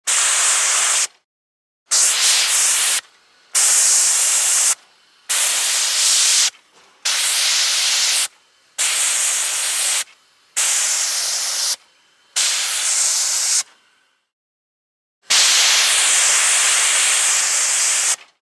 Звуки пара
Выдуваем пар или стремительный поток воздуха